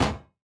Minecraft Version Minecraft Version 1.21.5 Latest Release | Latest Snapshot 1.21.5 / assets / minecraft / sounds / block / heavy_core / break3.ogg Compare With Compare With Latest Release | Latest Snapshot
break3.ogg